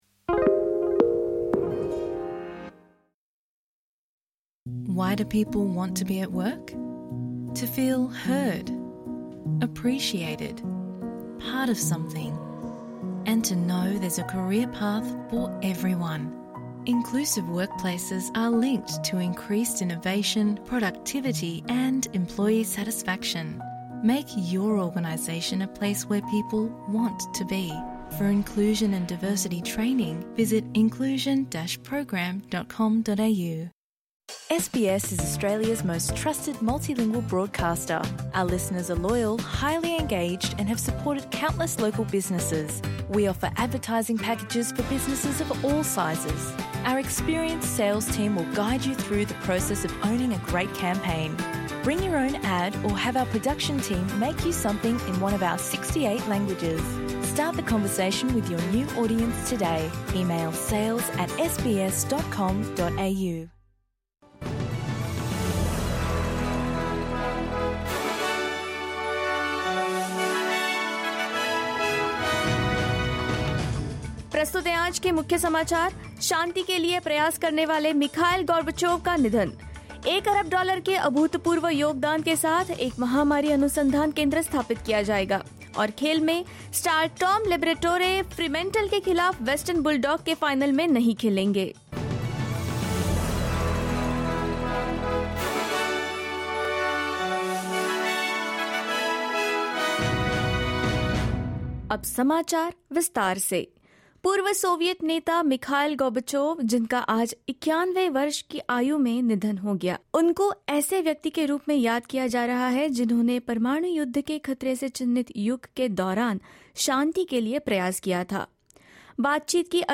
SBS Hindi News 31 August 2022: Nobel peace prize winner Mikhail Gorbachev passes away